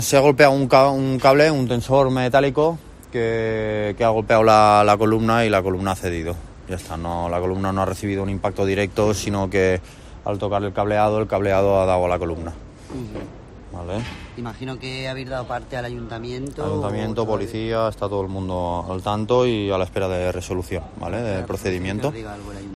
Un operario del montaje de la exposición comenta qué ha ocurrido con la imagen